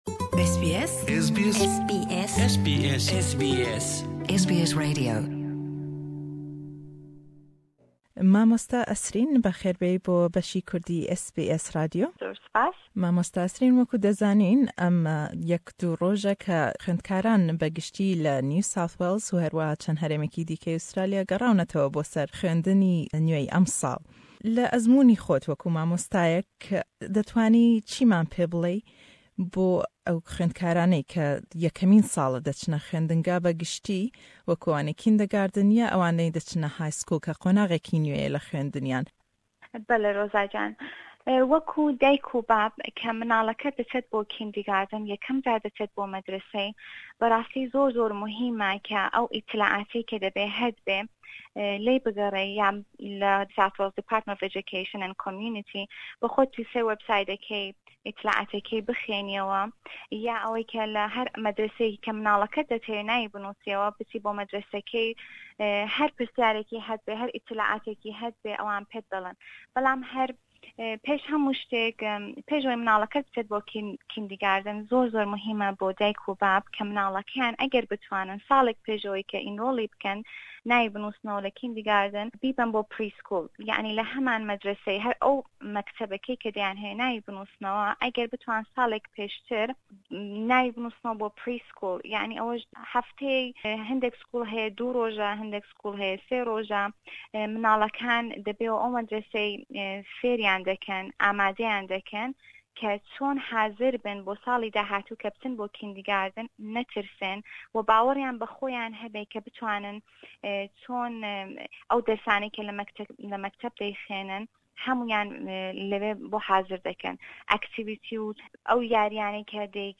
Herweha pirsîyarî lê dekeyn sebaret be beĺdarbûnî dadykan û bawkan le fêrbûnî mindallekaniyan.